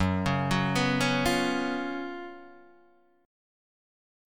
F# Major 7th Suspended 4th